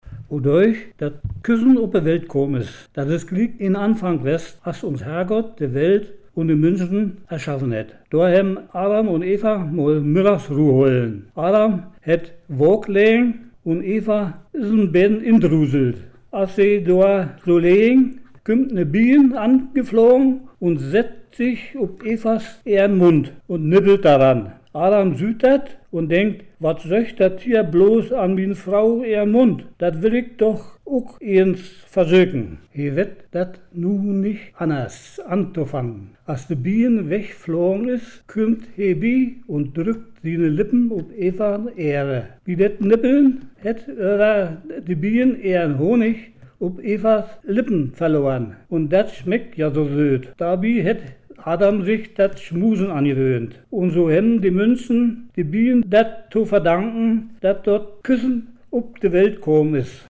Platt in Brandenburg – Hör man tau
Prignitz